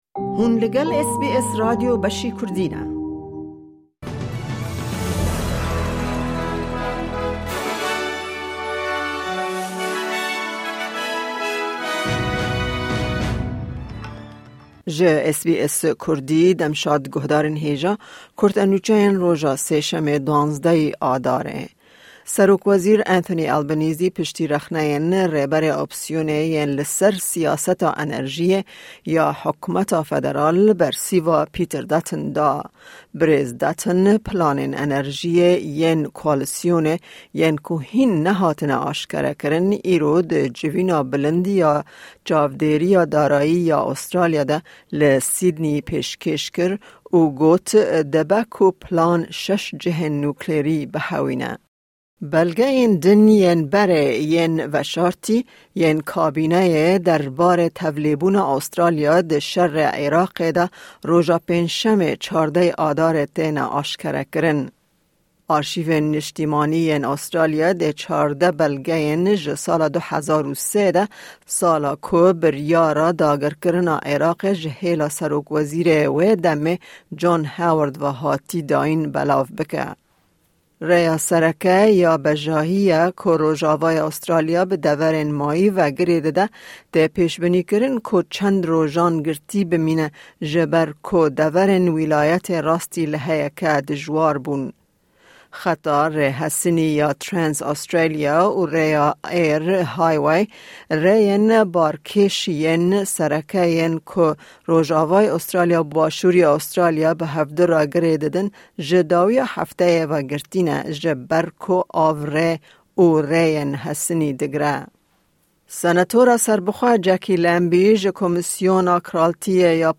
Kurte Nûçeyên roja Sêşemê 12î Adara 2024